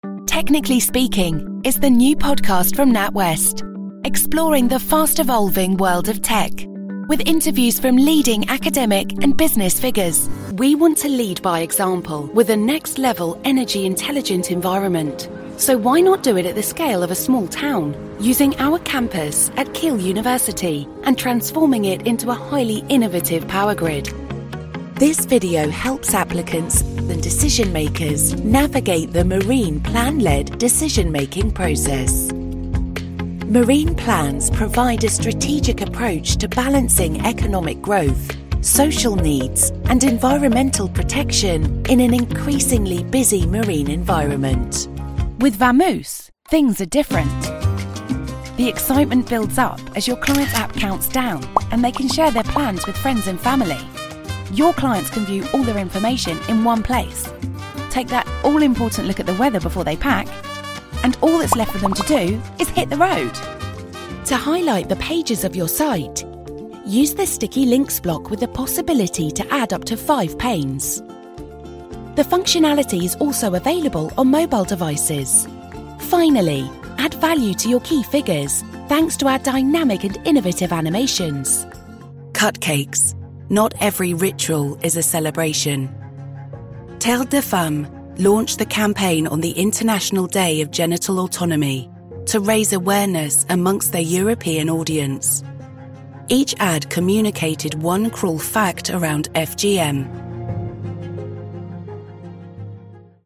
Inglés (Británico)
Comercial, Llamativo, Cálida, Natural, Seguro
Corporativo